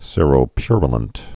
(sîrō-pyrə-lənt, -pyryə-)